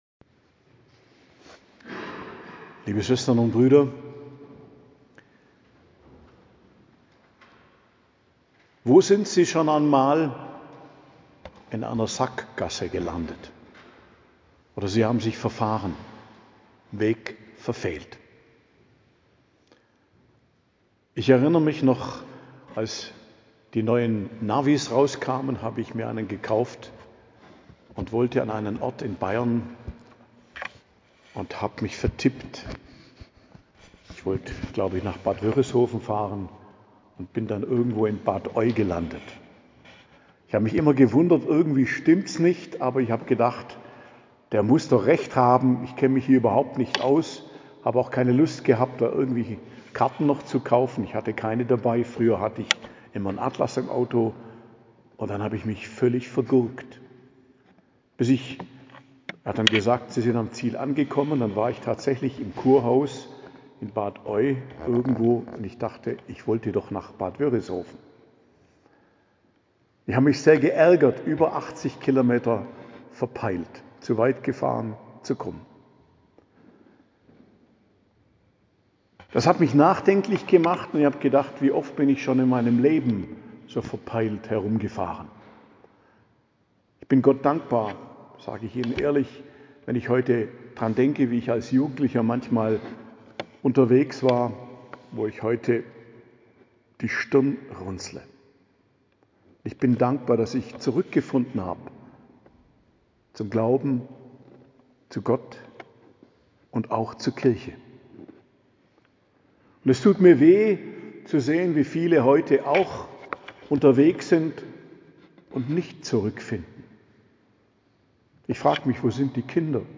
Predigt zum 2. Adventssonntag, 8.12.2024 ~ Geistliches Zentrum Kloster Heiligkreuztal Podcast